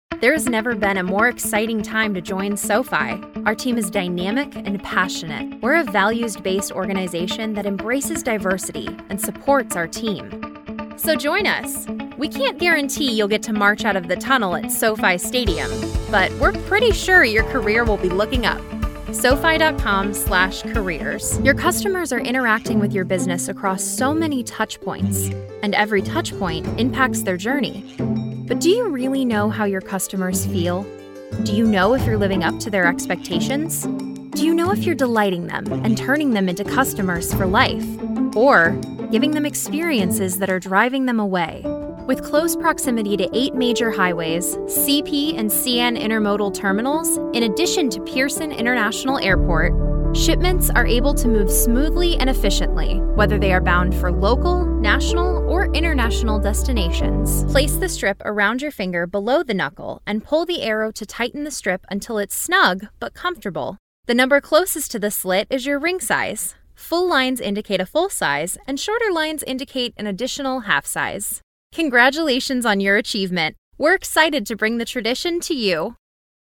Female Voice Over, Dan Wachs Talent Agency.
Upbeat, Modern, Warm, Conversational.
Corporate